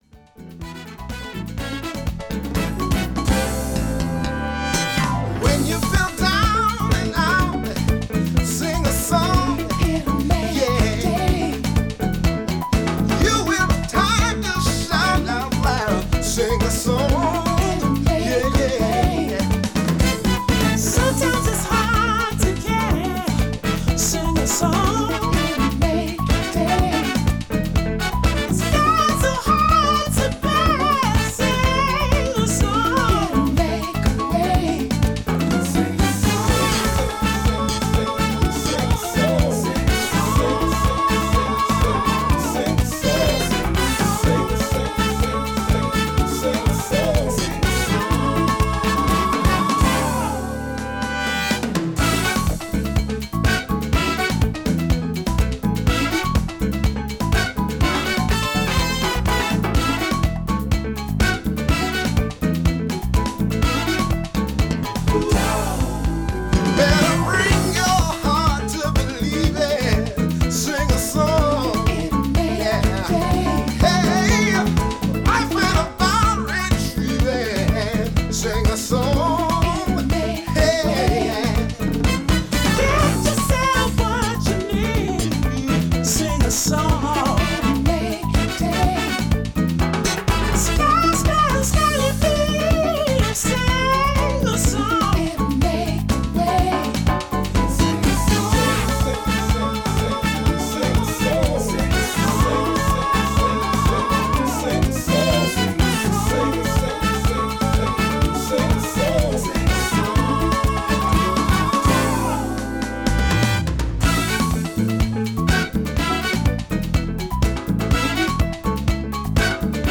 STYLE Soul